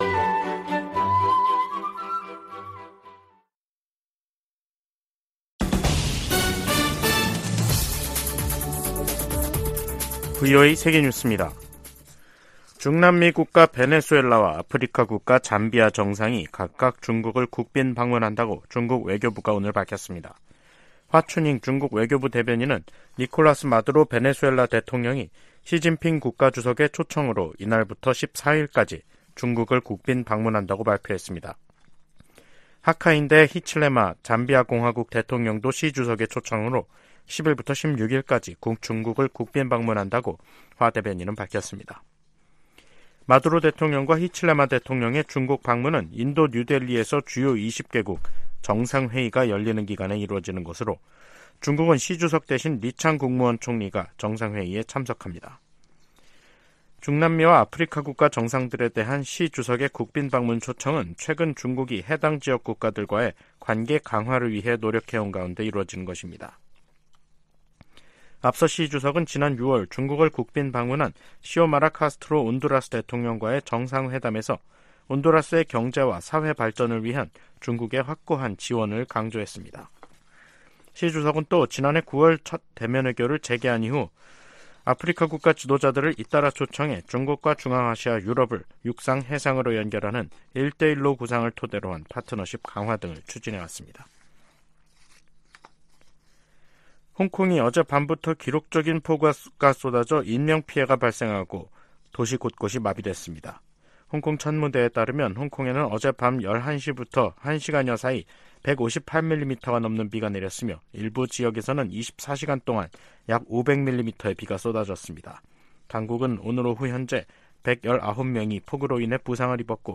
VOA 한국어 간판 뉴스 프로그램 '뉴스 투데이', 2023년 9월 8일 2부 방송입니다. 북한이 수중에서 핵 공격이 가능한 첫 전술 핵공격 잠수함인 '김군옥 영웅함'을 건조했다고 밝혔습니다. 인도네시아에서 열린 동아시아정상회의(EAS)에 참석한 카멀라 해리스 미국 부통령이 북한의 위협적 행동을 강력히 규탄했습니다. 러시아와 무기 거래를 하려는 북한은 '매우 위험한 게임'을 하는 것이라고 미국 상원 외교위원장이 지적했습니다.